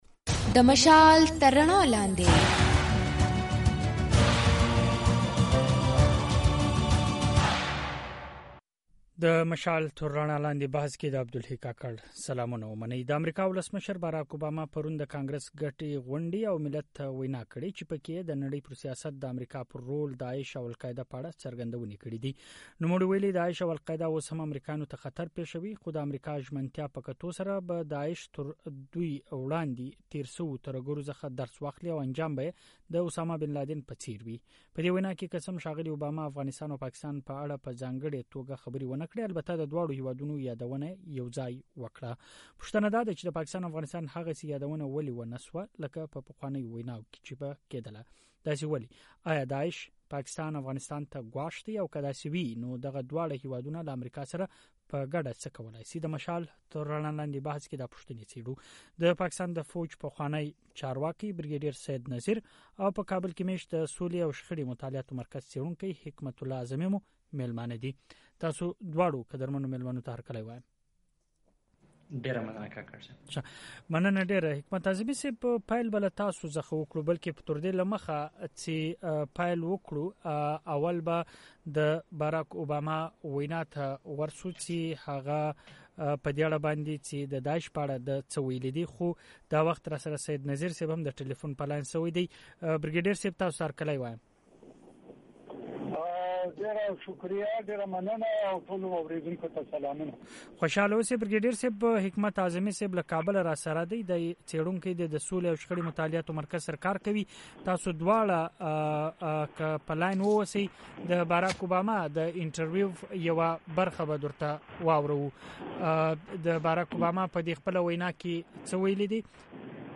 پوښتنه داده چې د پاکستان او افغانستان هغسي يادونه ولي ونشوه لکه چې به ښاغلي اوبامه په پخوانيو ويناوو کې کوله.؟ د مشال تر رڼا لاندي بحث پر دې موضوع خبري شوي دي.